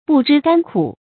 不知甘苦 注音： ㄅㄨˋ ㄓㄧ ㄍㄢ ㄎㄨˇ 讀音讀法： 意思解釋： 甘苦：甜和苦，多偏指苦。